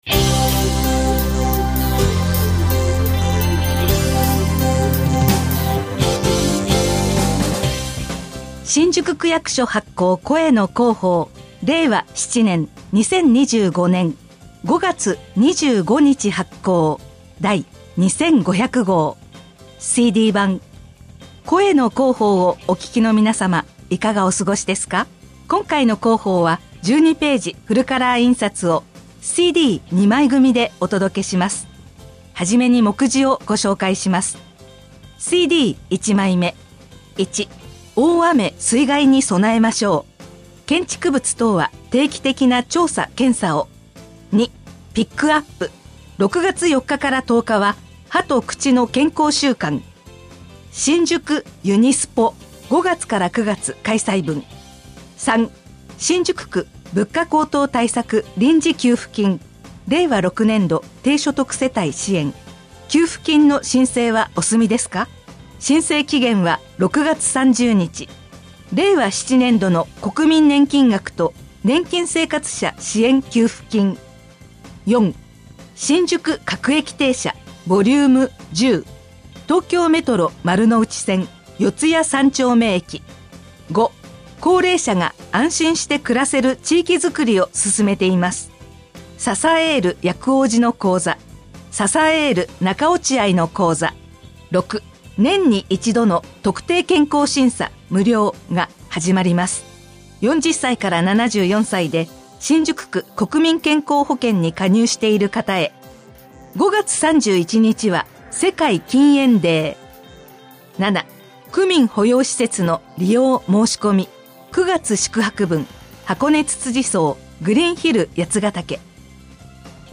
声の広報（目次） ［MP3形式：5.31MB］（新規ウィンドウ表示） (1)CD1枚目（大雨・水害に備えましょう、6月4日～10日は歯と口の健康週間、新宿ゆにスポ！